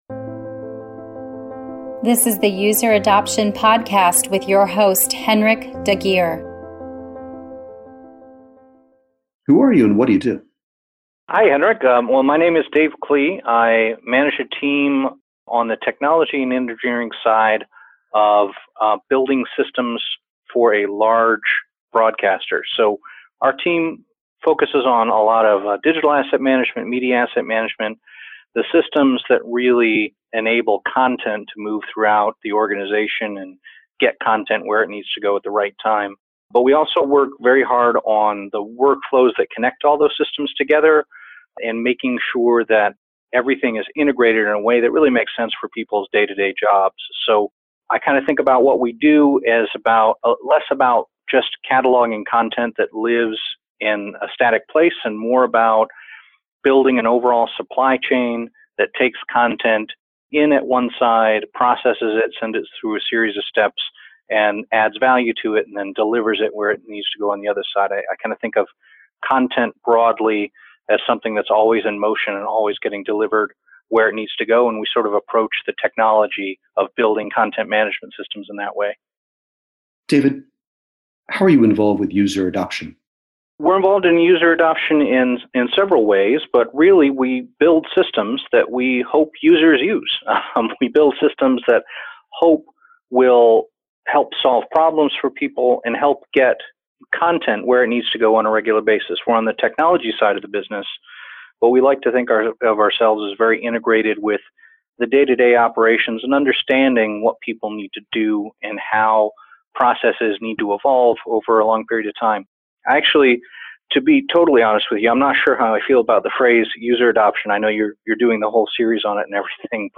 User Adoption / Interview